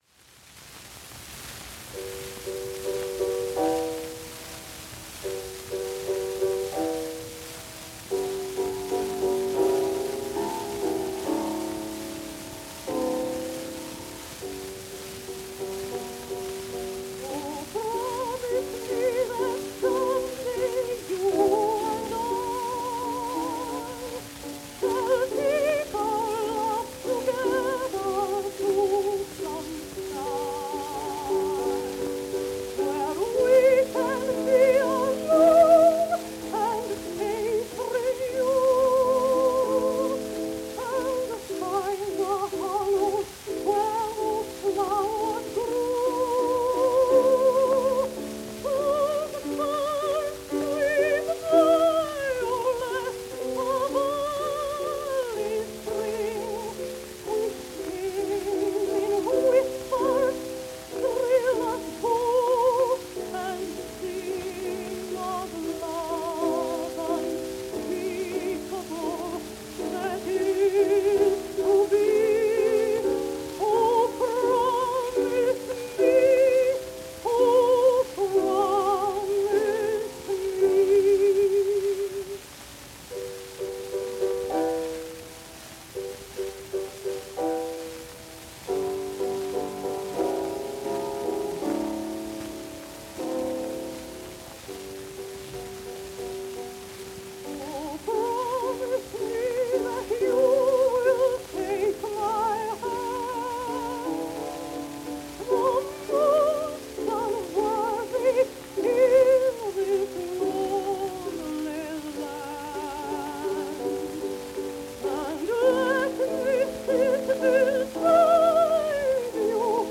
mezzo-soprano
Genre: vocal.